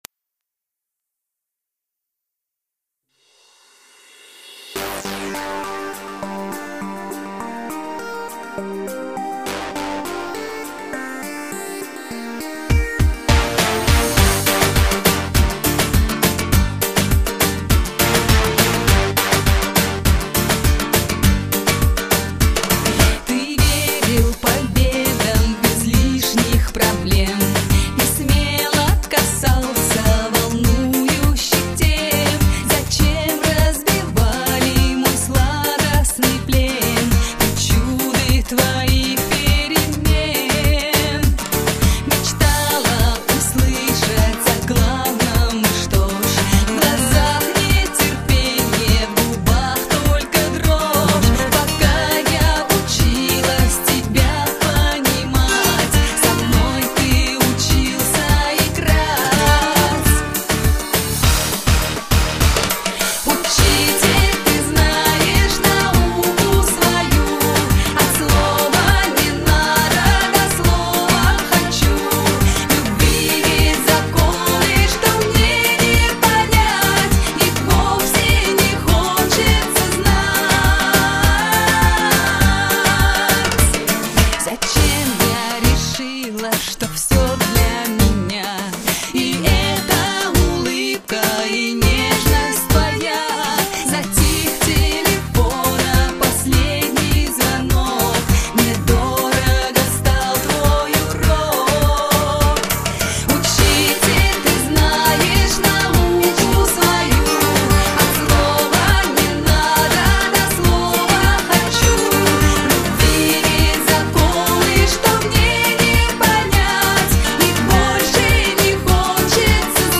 Жанр: Эстрада, Попса